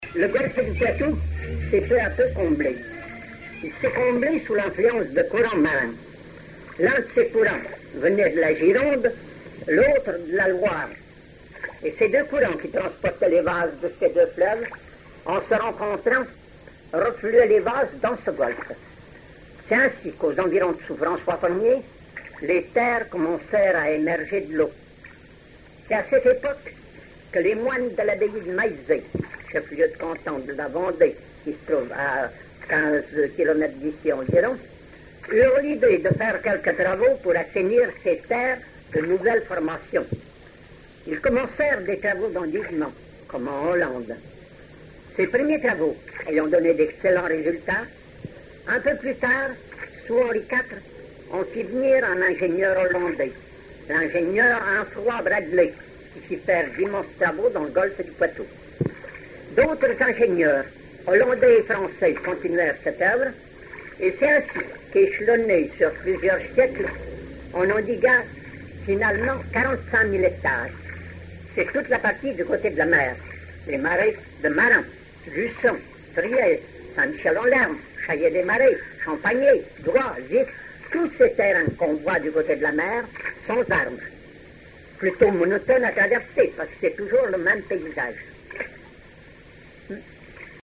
interviews d'historiens et cuisiniers vendéens
Catégorie Témoignage